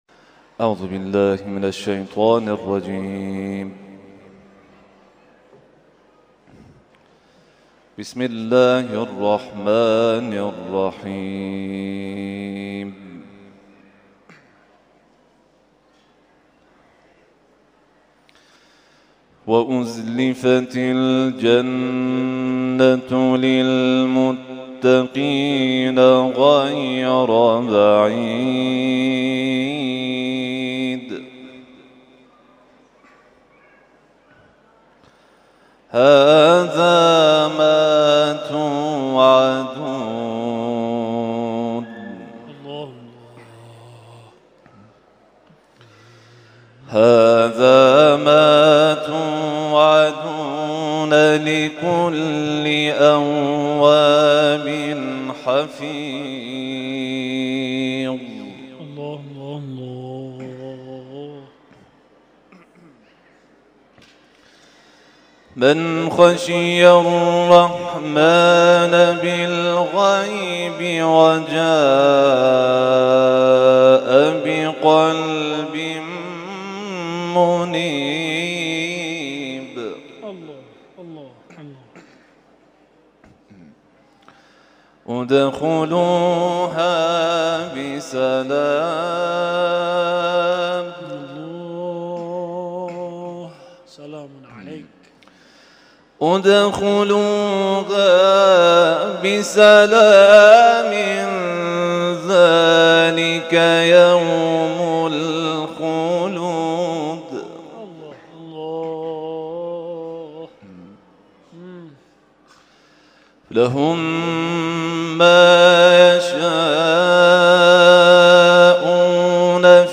گروه جلسات و محافل: محفل انس با قرآن این هفته آستان عبدالعظیم الحسنی(ع) با تلاوت قاریان ممتاز و بین‌المللی کشورمان برگزار شد.